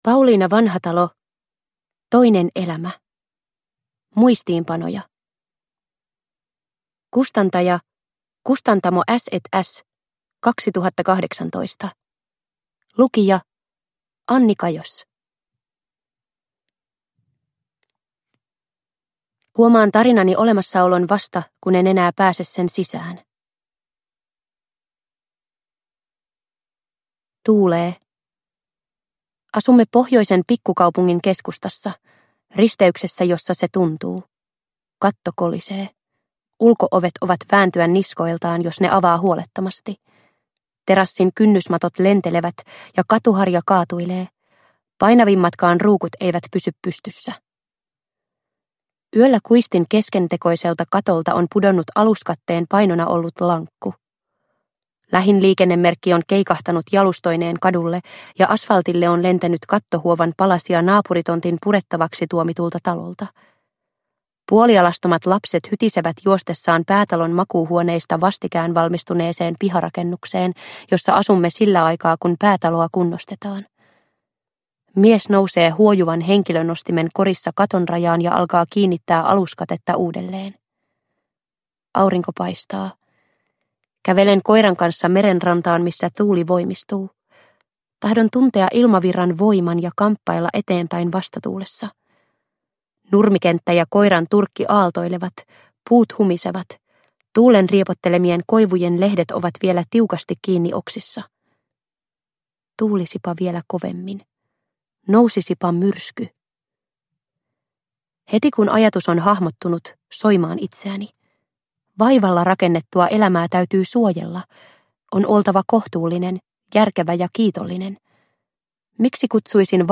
Toinen elämä – Ljudbok – Laddas ner